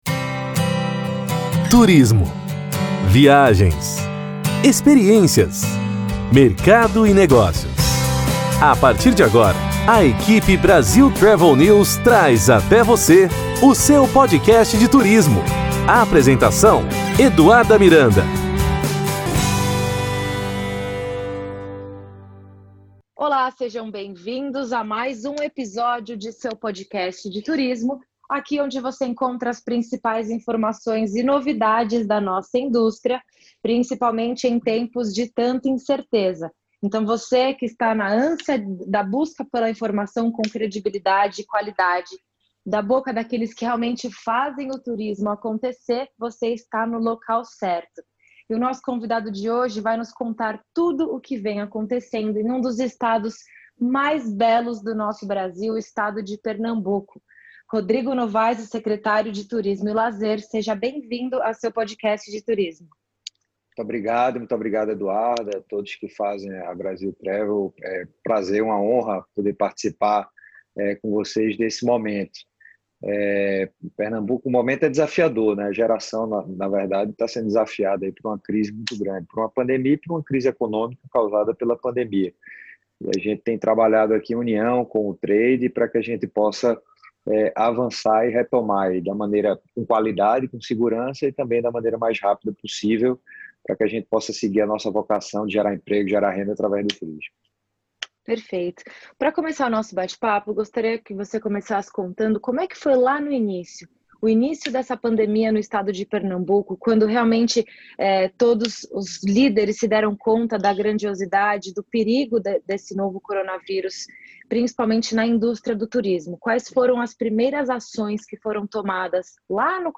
Rodrigo Novaes - secretário de turismo e lazer de Pernambuco, conversou com o Seu Podcast de Turismo sobre os impactos da pandemia no estado.
Confira a entrevista completa através do Seu Podcast de Turismo , nas plataformas Spotify, Google Play e Apple.